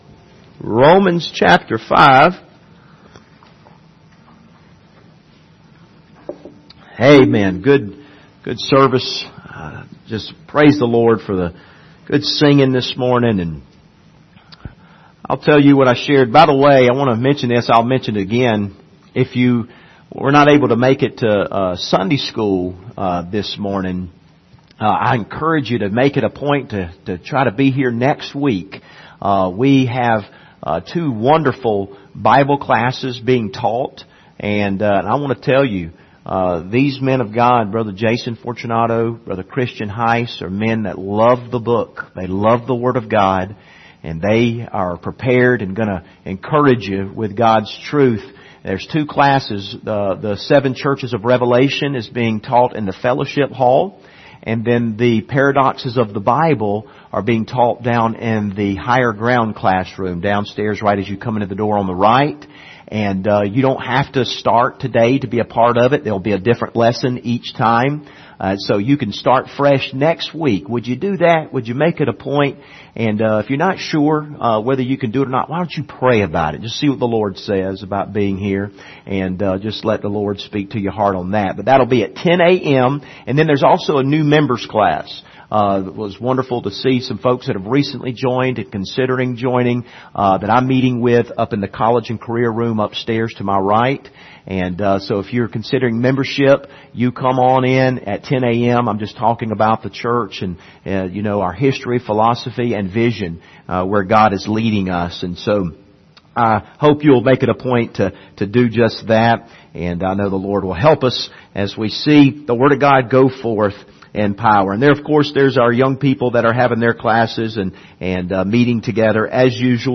The Just Shall Live By Faith Passage: Romans 5:5-11 Service Type: Sunday Morning Topics